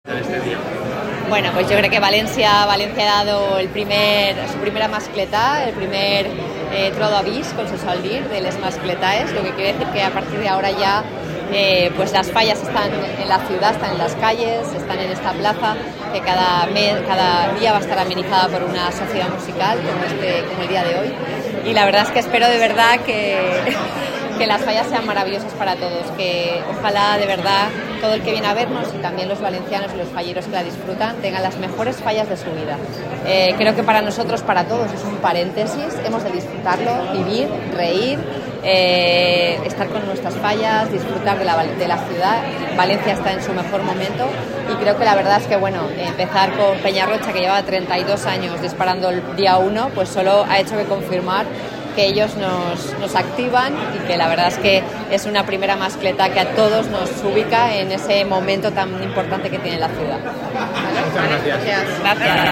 Desde primeras horas de la mañana, la Plaza del Ayuntamiento de Valencia fue un hervidero de gente.
El disparo de Pirotecnia Peñarroja: potencia y ritmo fallero La encargada de abrir el ciclo fue la Pirotecnia Peñarroja , que desplegó un disparo clásico, contundente y muy rítmico , fiel al estilo valenciano. Con 141,383 kilos de pólvora , la mascletà fue de menos a más, construyendo una progresión sonora que fue atrapando al público hasta desembocar en un terremoto final que hizo vibrar balcones y fachadas. El cierre, seco y poderoso, provocó una ovación unánime.
Mascleta-audio.mp3